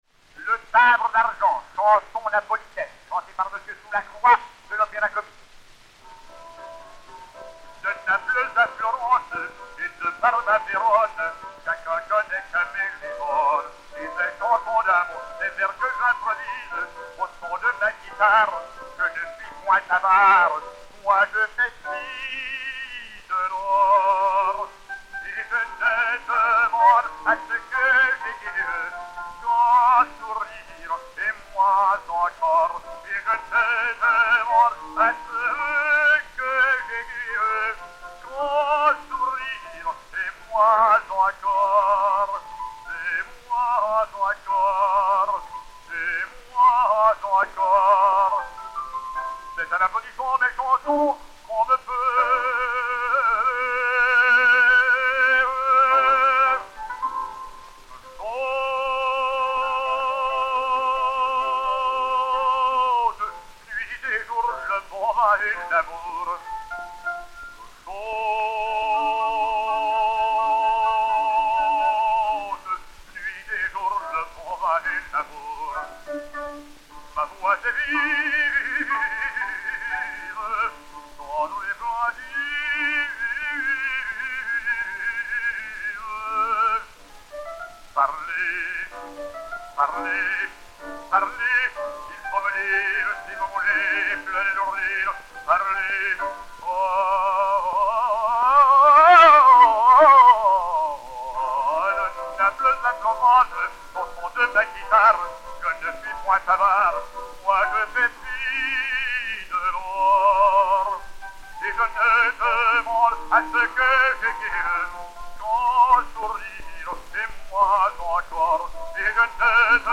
Chanson napolitaine de l'Acte II
Gabriel-Valentin Soulacroix (Spiridion) et Piano
Pathé saphir 90 tours n° 3722, enr. en 1903